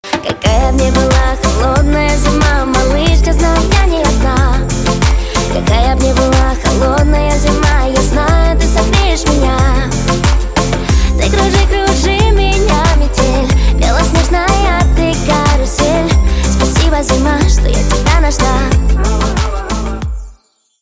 поп
красивые
женский вокал
добрые